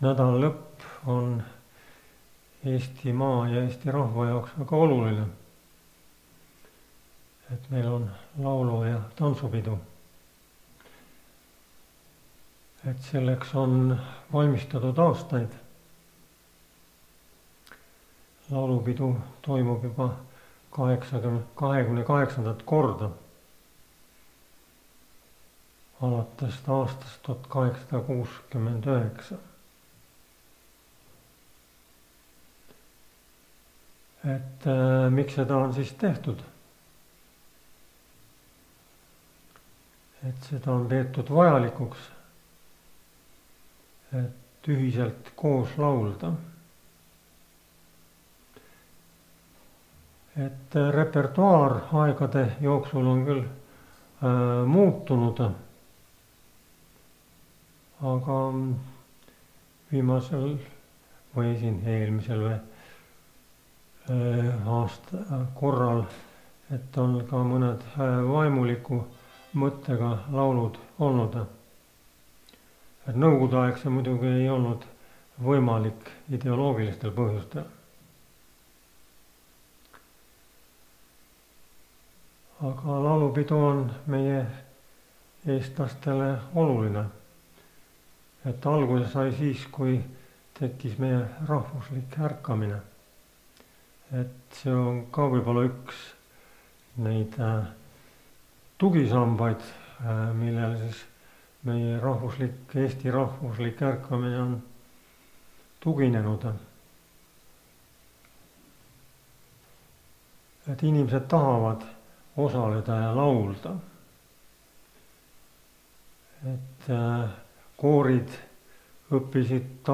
Laulupeo lainel (Rakveres)
Jutlused